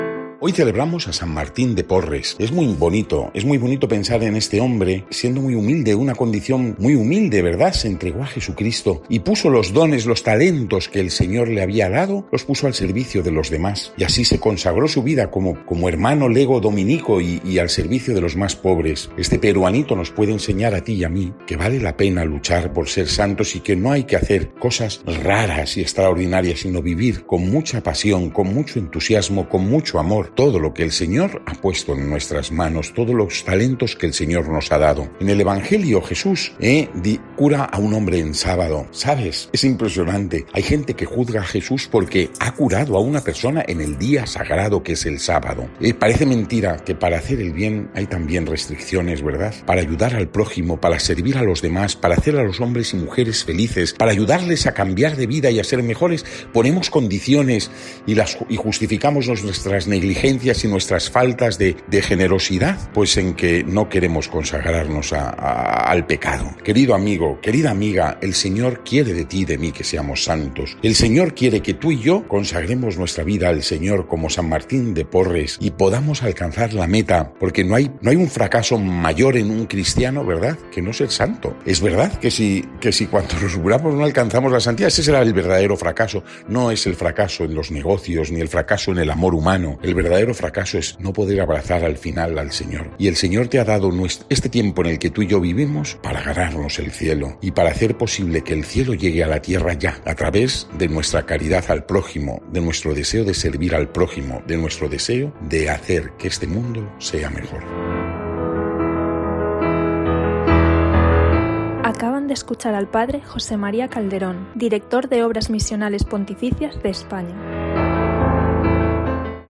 Evangelio según san Lucas (14,1.7-11) y comentario